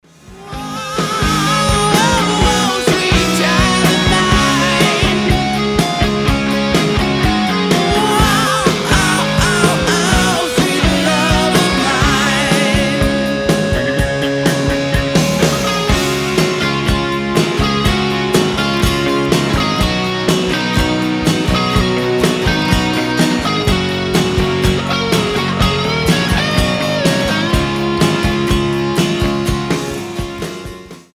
80-е
тяжелый рок
Glam Metal